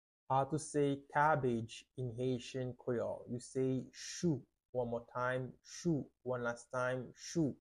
How to say “Cabbage” in Haitian Creole – “Chou: pronunciation by a native Haitian Teacher
“Chou” Pronunciation in Haitian Creole by a native Haitian can be heard in the audio here or in the video below: